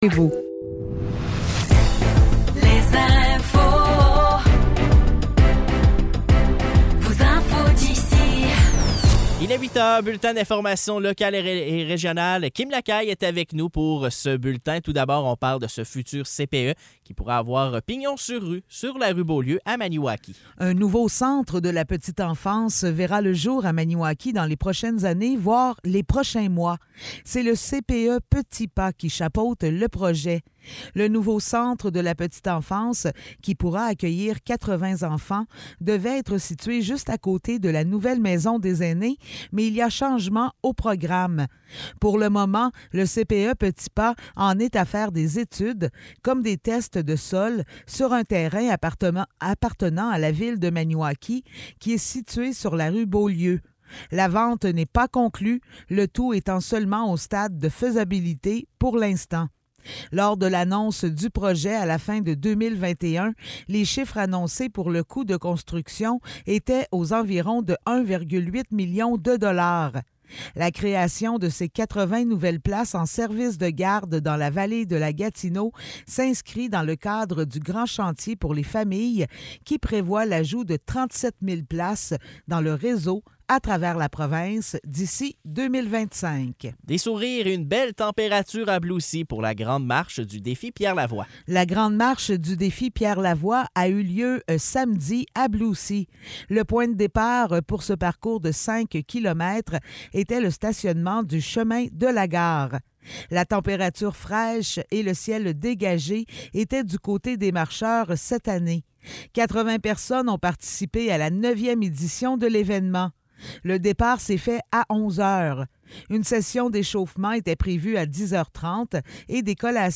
Nouvelles locales - 16 octobre 2023 - 8 h